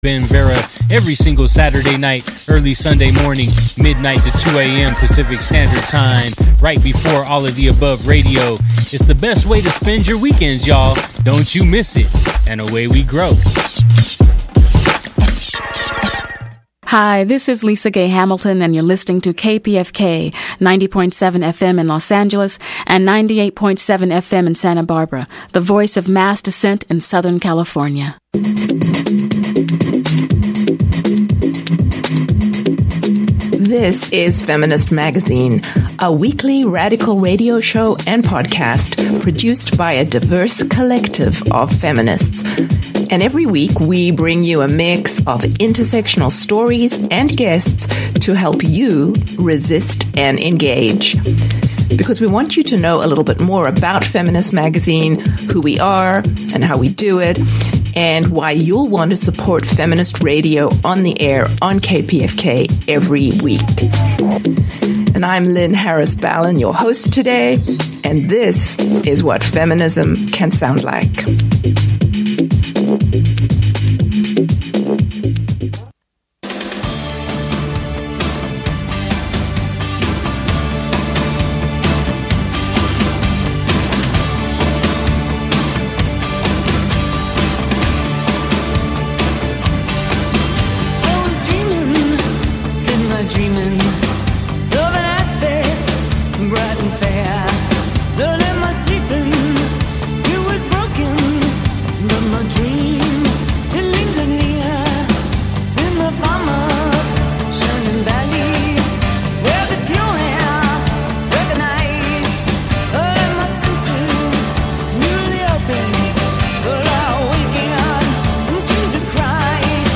Feminist Magazine is the weekly Southern California radio show with intersectional feminist perspectives.